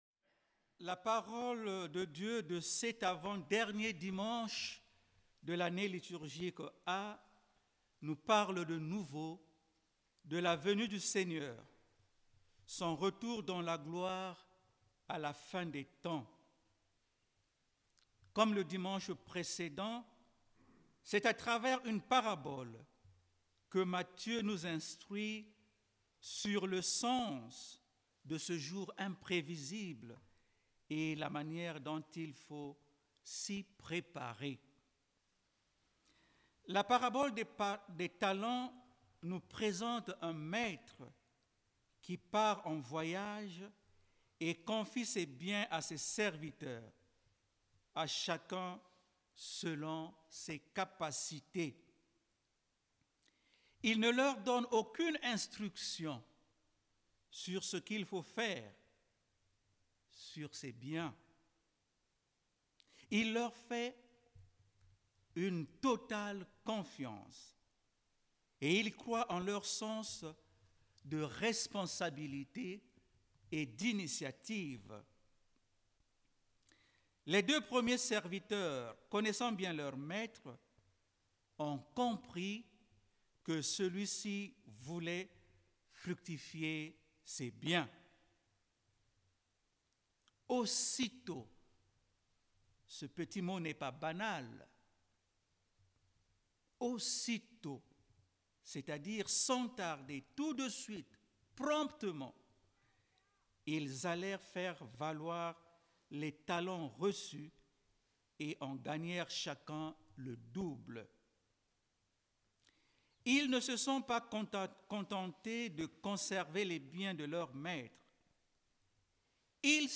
Pour ce dimanche de la 33ème semaine du Temps Ordinaire